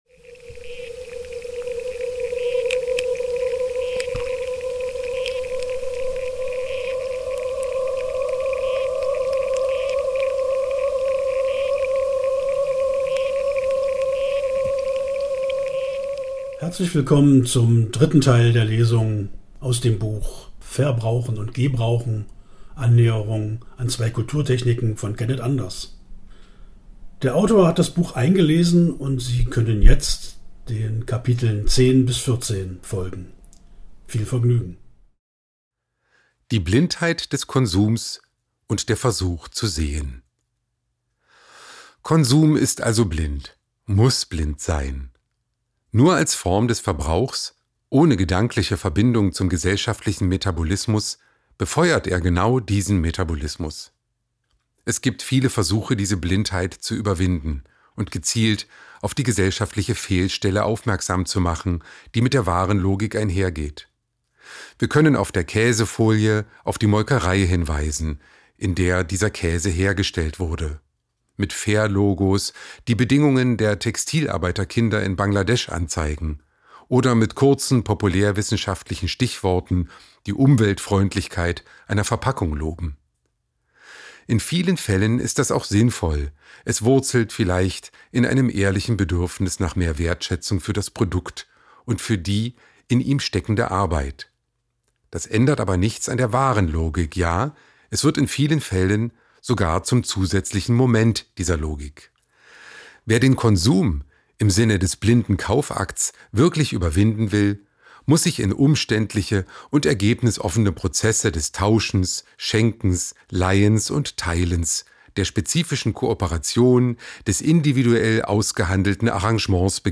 Der Autor hat sein Buch eingelesen und wir laden Sie ein, seinem Selbstversuch zuzuhören.